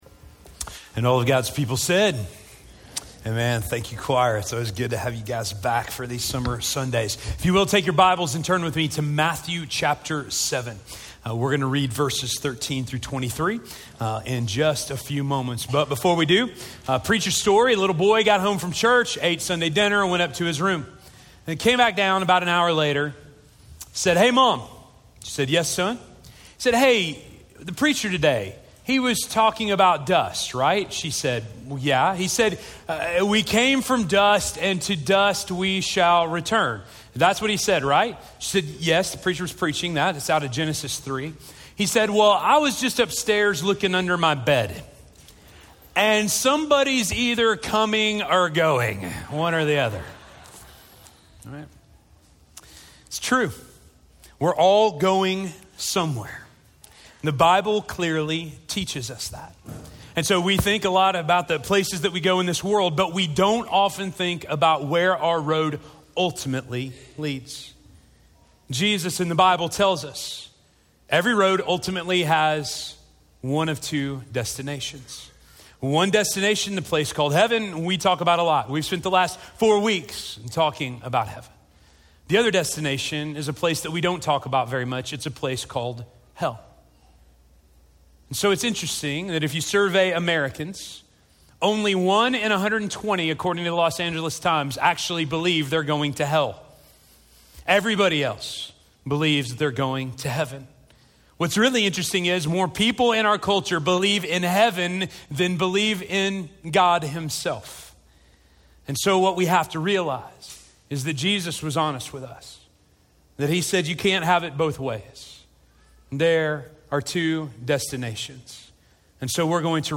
The Default Destination - Sermon - Station Hill